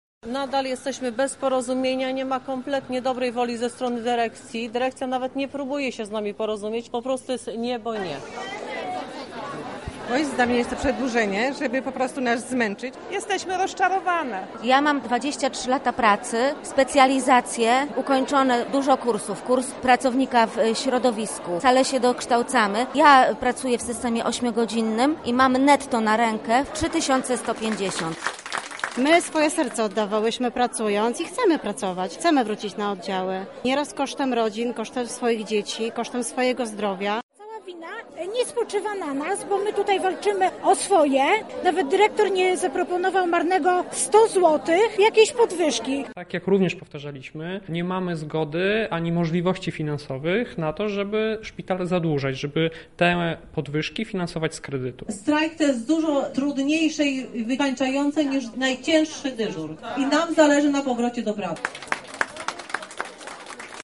Protestującym towarzyszyła nasza reporteka.